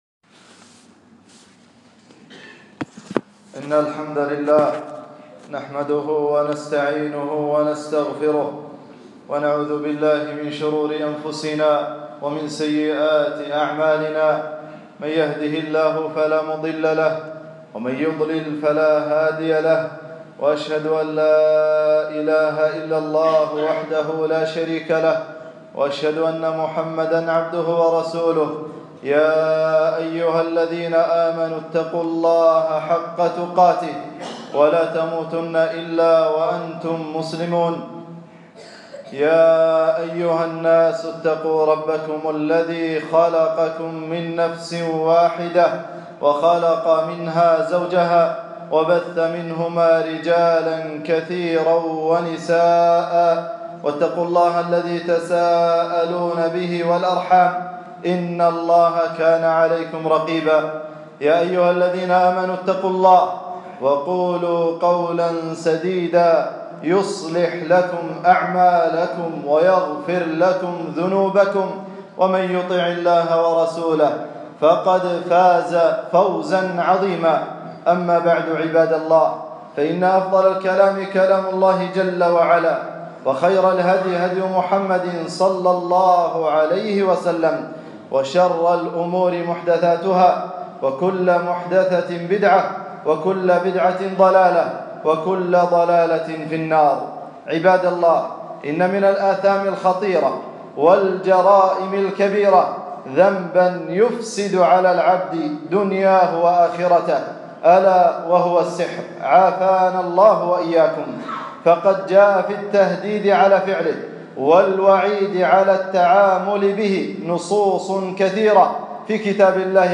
خطبة - خطورة السحر والسحرة 1-3-1440 هــ